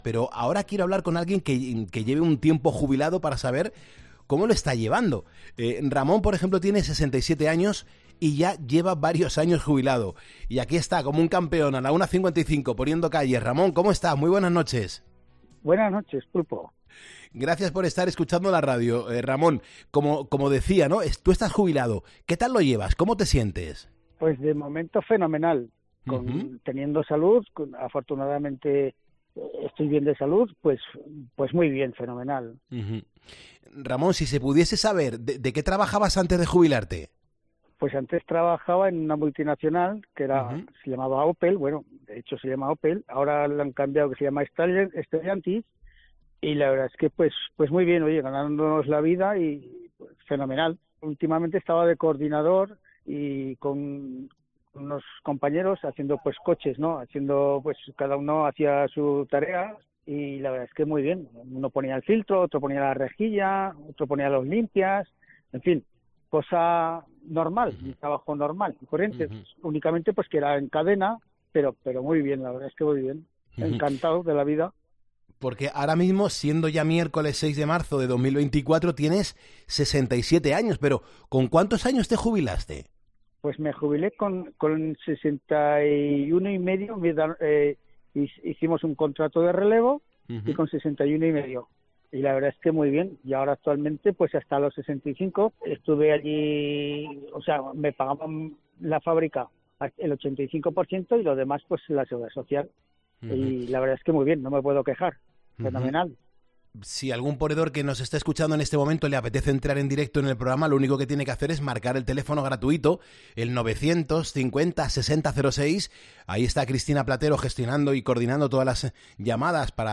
Un jubilado